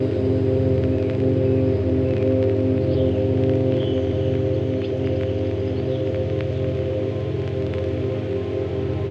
Fm-DirtyVinylAtmos.wav